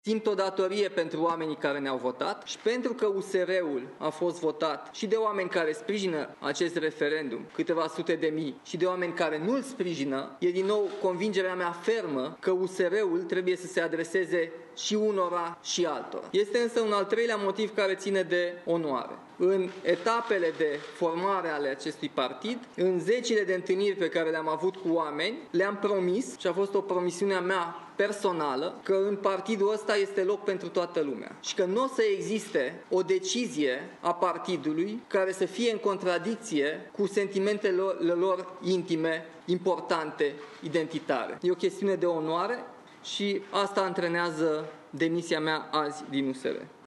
Nicuşor Dan a spus, într-o conferinţă de presă, că există trei motive care au stat la baza demisiei sale.